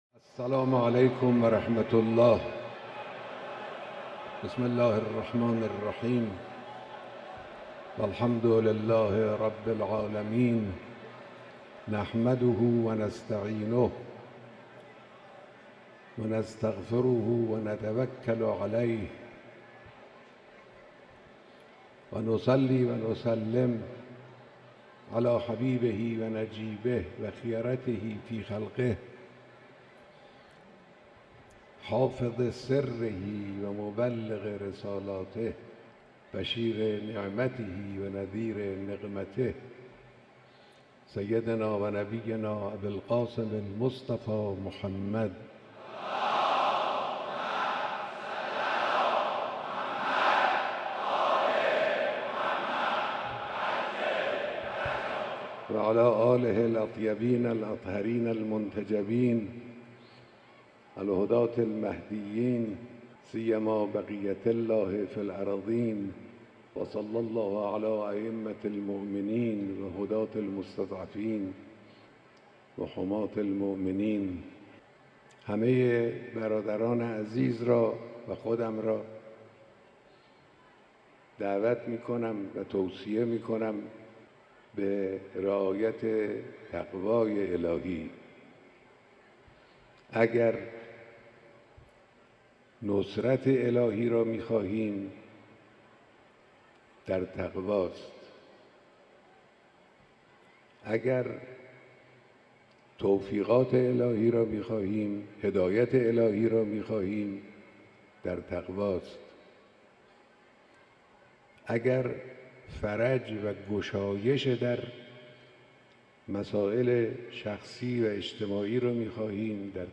اقامه نماز جمعه به امامت رهبر معظم انقلاب اسلامی
خطبه‌های نمازجمعه - خطبه اول